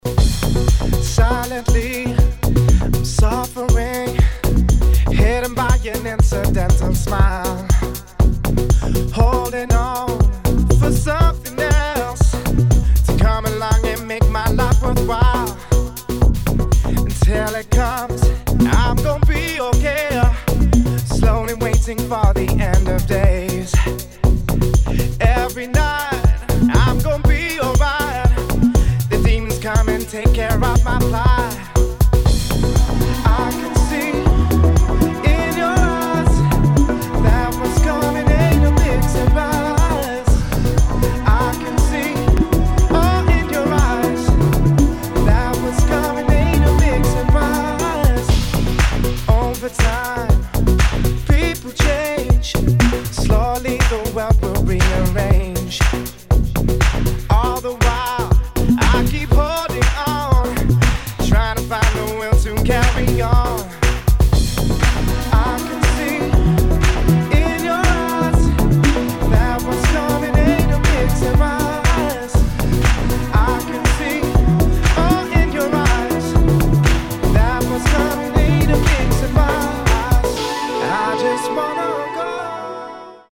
[ HOUSE | CROSSOVER ]